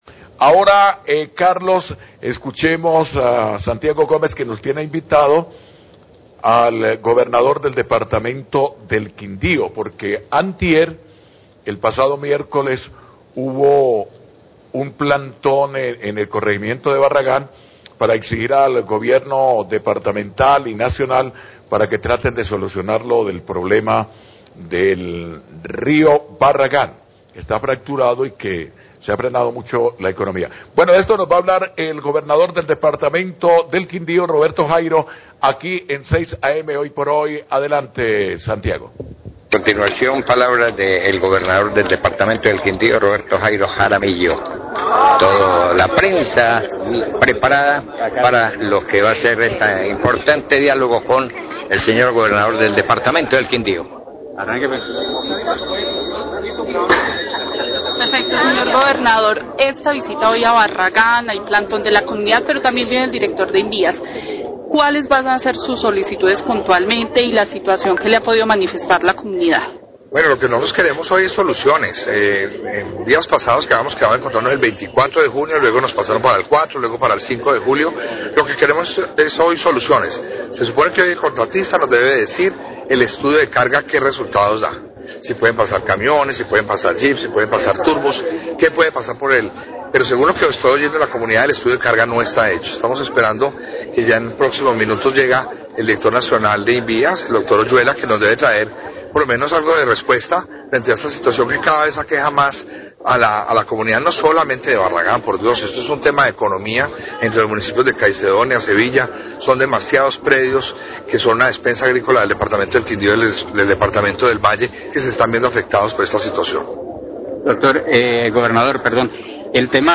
Radio
En rueda de prensa en el sector de Barragán, el Gobernador del Quindío, Roberto Jairo Jaramillo, expresa su descontento con  el contratista por la demora en las obras de reforzamiento del puente de Barragan. Pide celeridad en ellas y una respuesta sobre las pruebas d ecarga que habían pormetido se desarrollaría en la semana anterior.